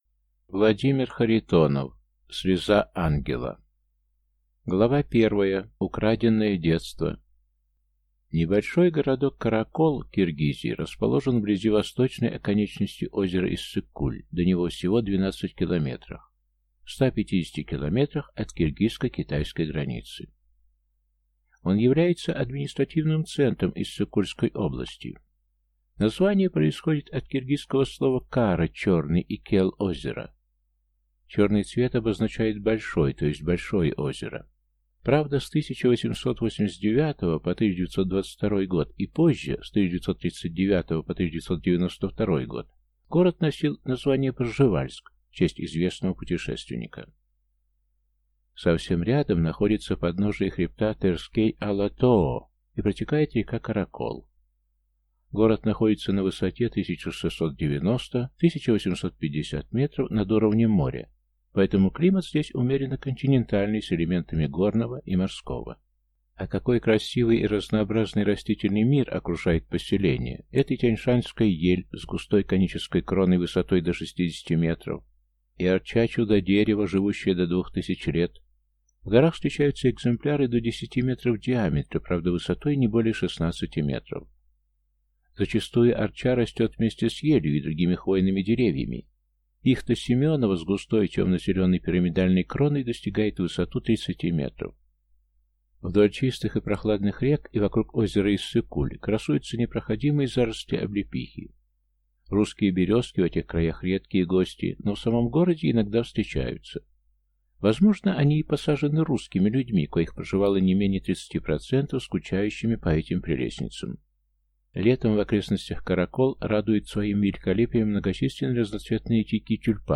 Аудиокнига Слеза Ангела | Библиотека аудиокниг